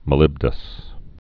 (mə-lĭbdəs)